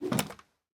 Minecraft Version Minecraft Version snapshot Latest Release | Latest Snapshot snapshot / assets / minecraft / sounds / item / crossbow / loading_end.ogg Compare With Compare With Latest Release | Latest Snapshot
loading_end.ogg